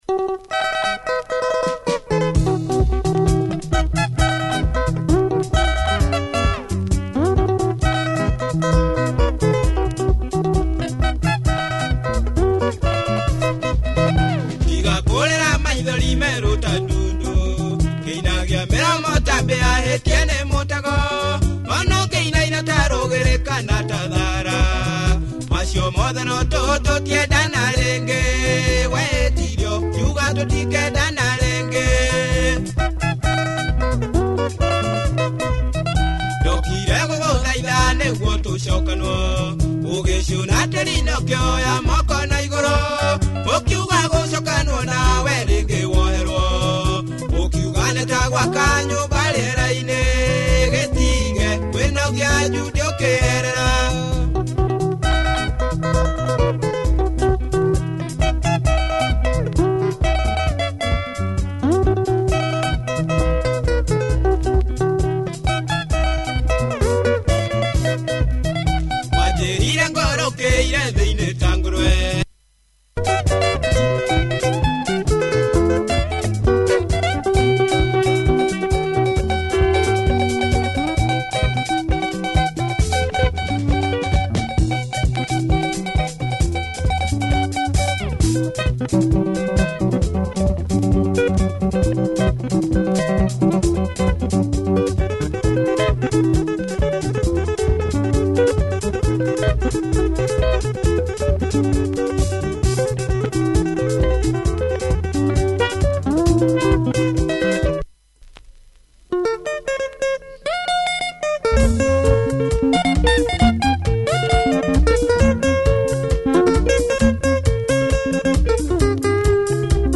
Nice Kikuyu benga https